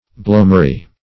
blomary - definition of blomary - synonyms, pronunciation, spelling from Free Dictionary
blomary - definition of blomary - synonyms, pronunciation, spelling from Free Dictionary Search Result for " blomary" : The Collaborative International Dictionary of English v.0.48: Blomary \Blom"a*ry\, n. See Bloomery .